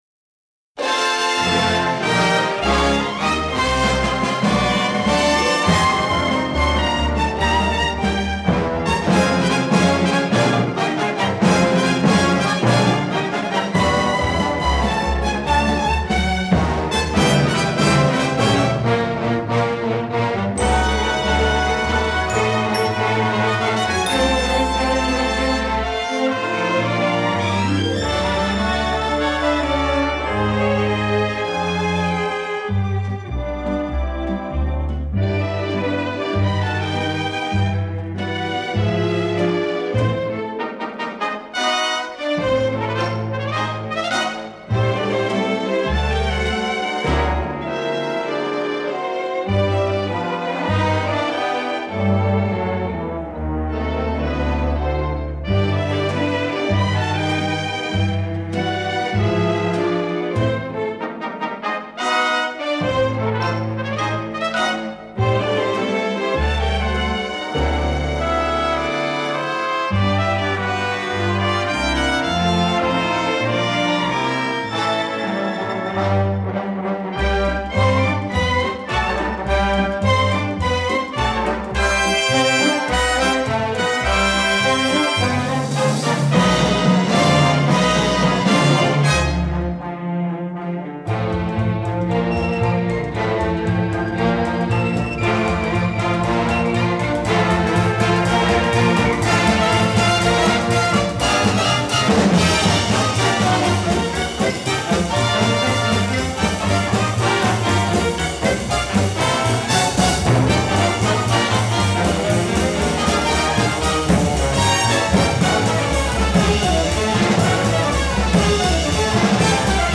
Original French Cast Recording